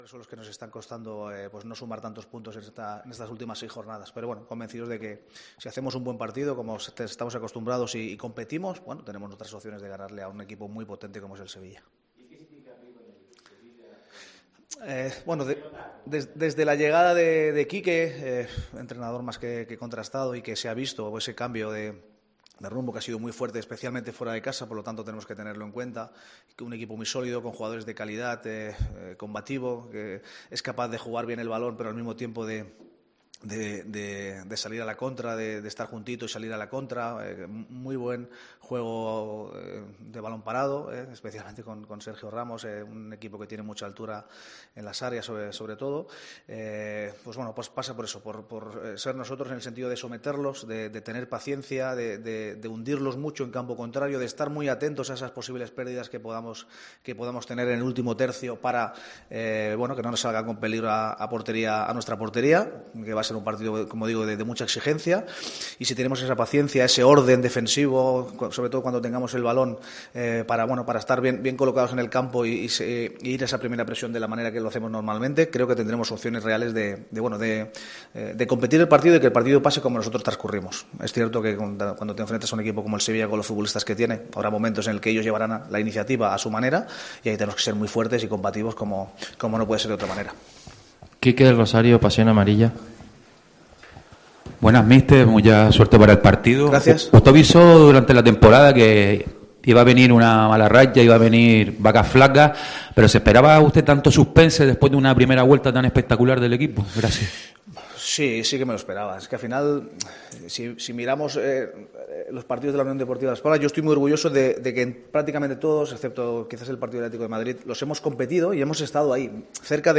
Corregir esos fallos es, a juicio del técnico catalán, la premisa para intentar imponerse al conjunto hispalense, como ha repetido en varias ocasiones este viernes durante la rueda de prensa previa al choque.